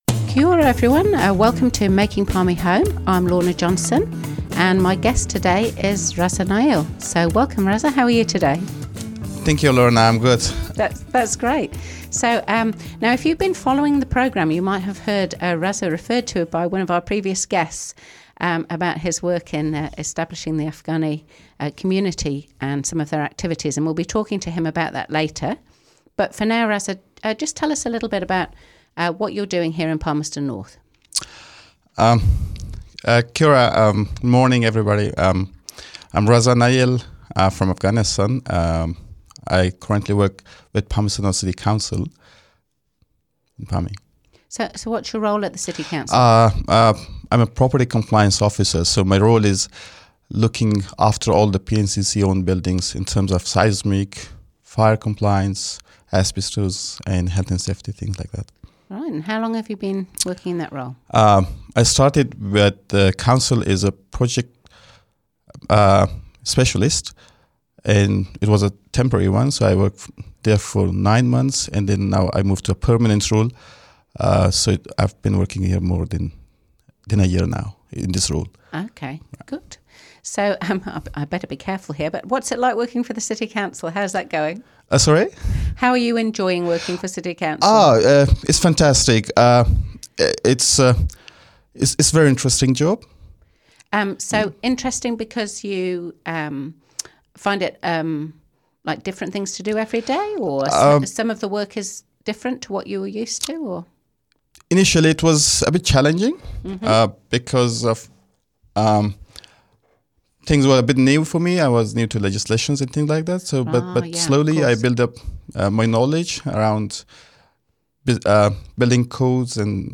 Palmerston North City Councillor Lorna Johnson hosts "Making Palmy Home", a Manawatū People's Radio series interviewing migrants about their journeys to Palmerston North.
interview